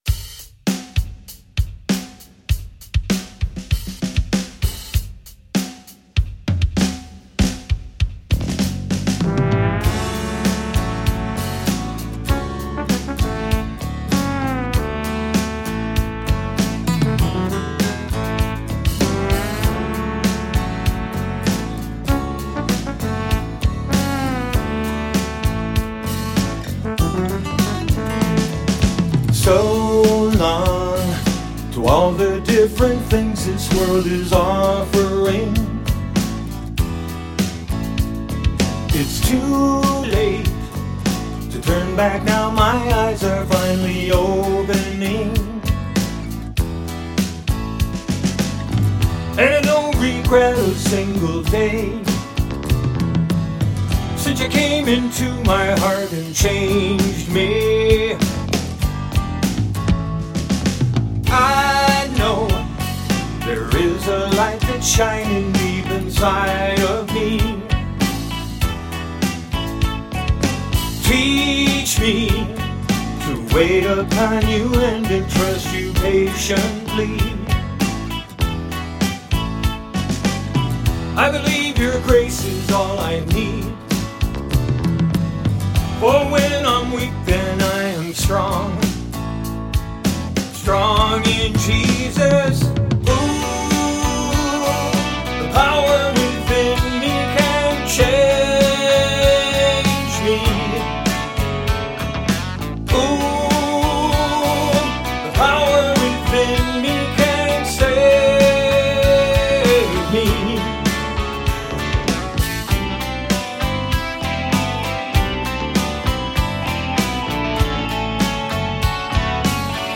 #4 of 4 upbeat and uplifting songs.